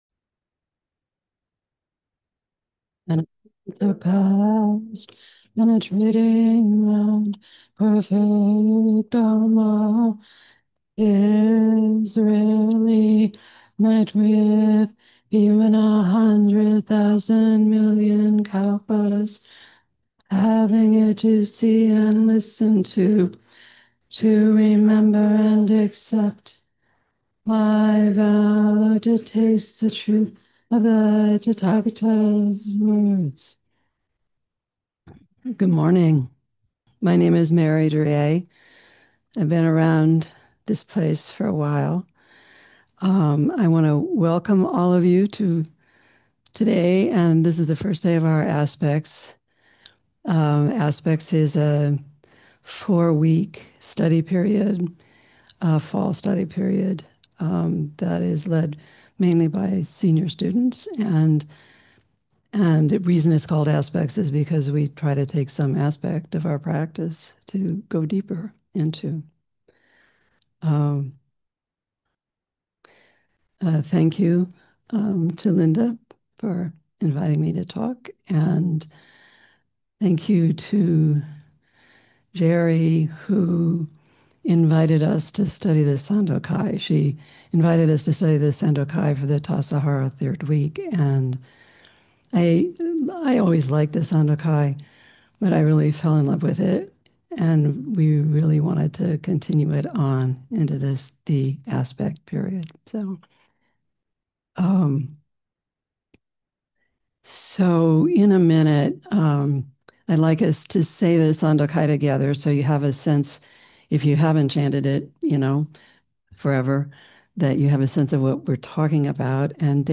Sandokai: One Mind; Difference And Equality Berkeley Zen Center Dharma Talks podcast To give you the best possible experience, this site uses cookies.